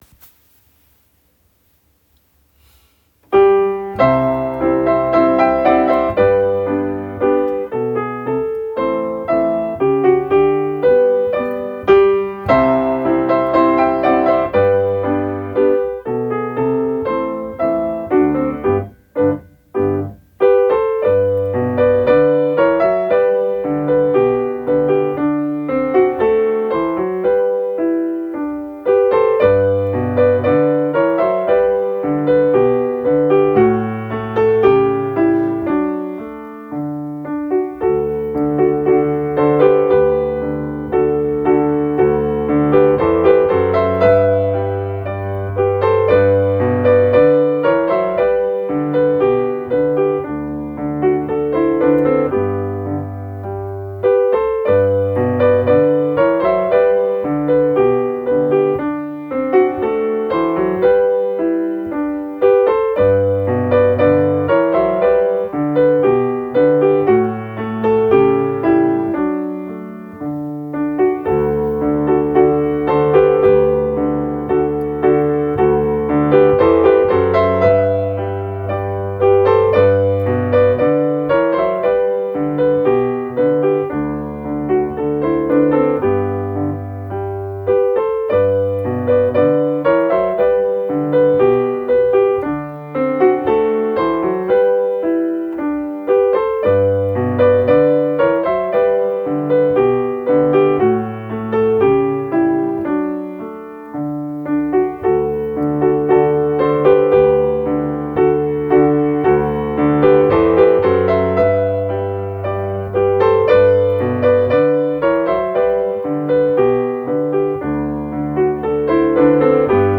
昭和の校歌　伴奏